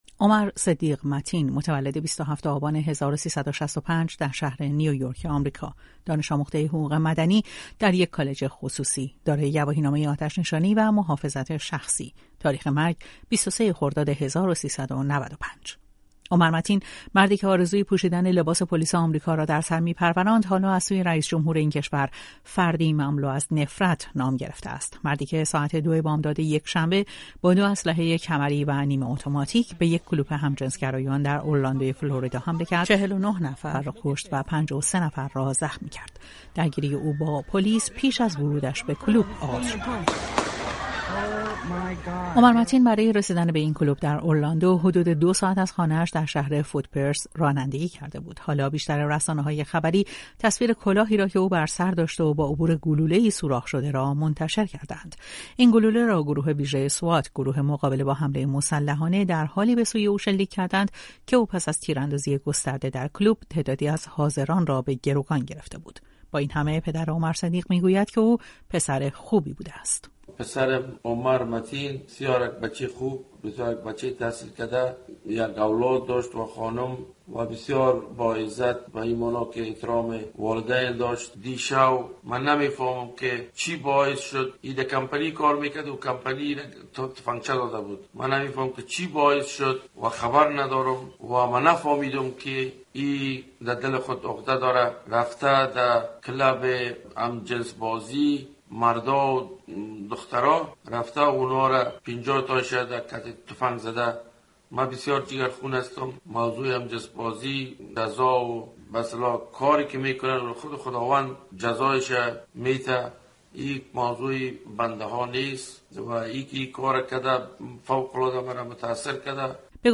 از رادیو فردا